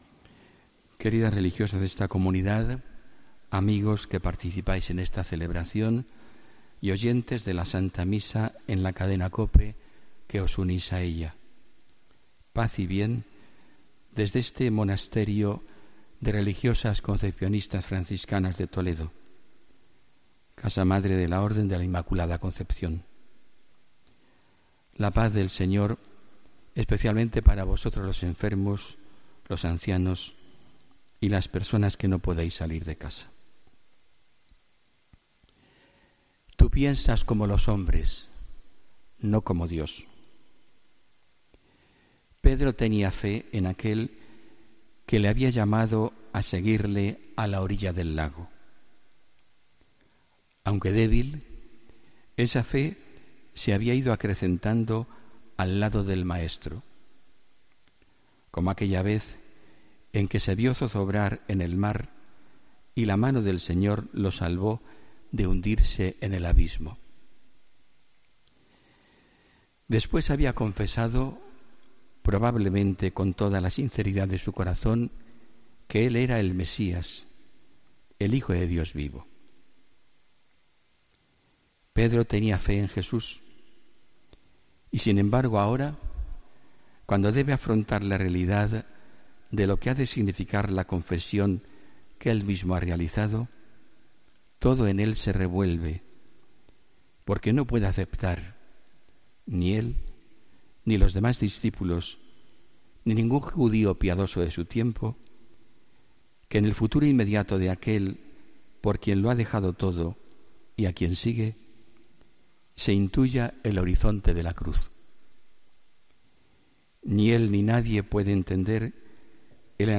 Homilía del domingo 3 de septiembre de 2017